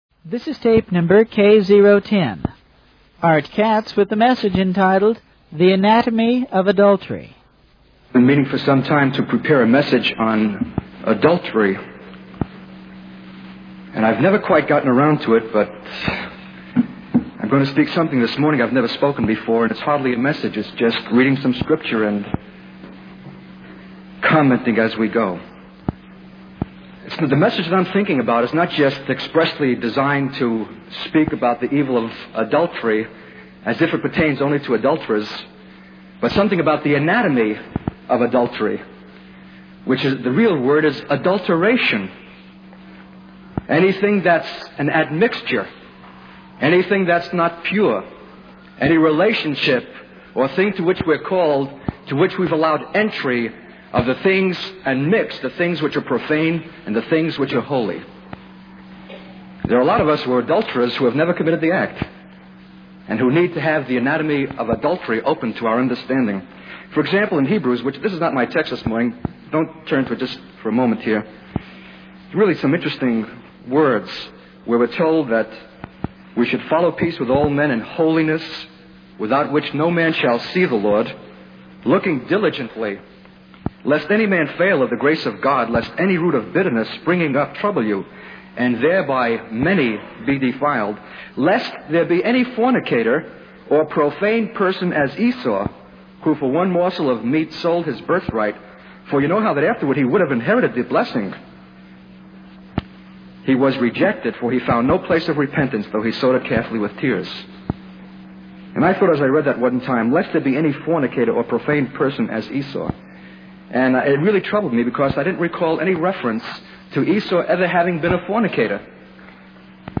In this sermon, the speaker emphasizes the importance of taking action and living a holy life. He urges the audience to not just go through the motions of attending church, but to truly understand their responsibility as believers.